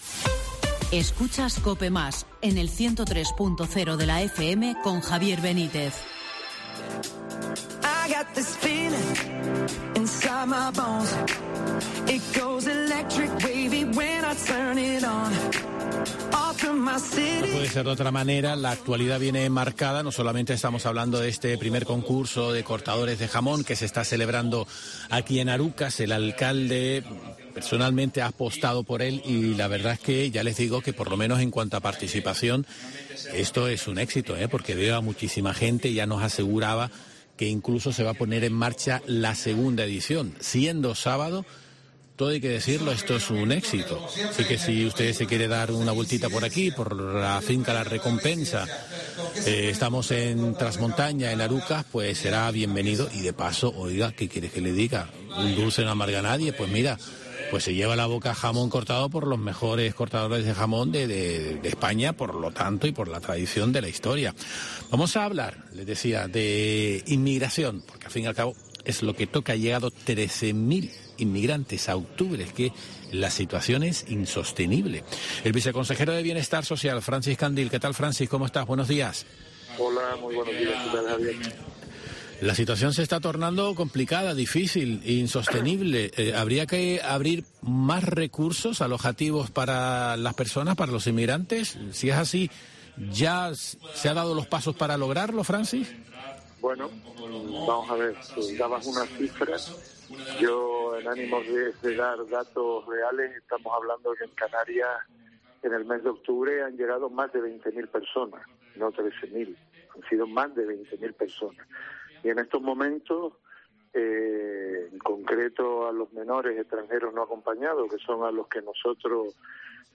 El viceconsejero de Bienestar Social del Gobierno de Canarias, Francis Candil, ha remarcado en los micrófonos de Herrera en COPE Gran Canaria que los alcaldes de Canarias no “hacen cola” para instalar en su municipio un centro de acogida a menores inmigrantes no acompañados.